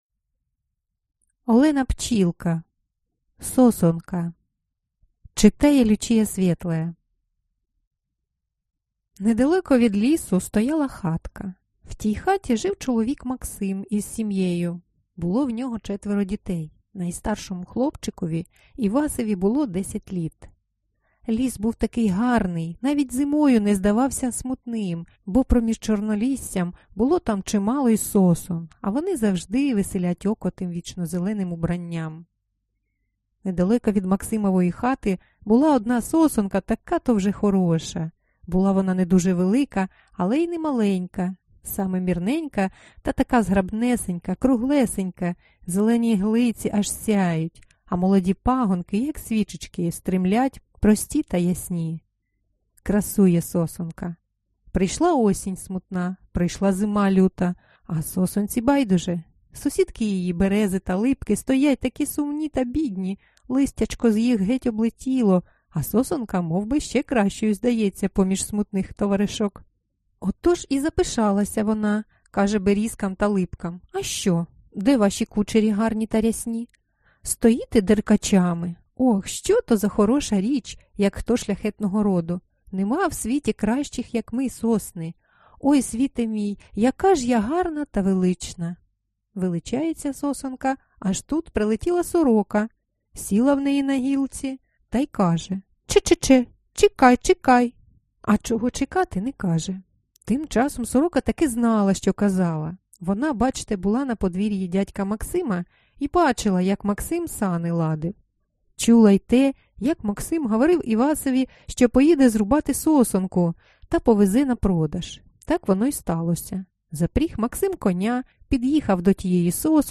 Аудиокнига Сосонка | Библиотека аудиокниг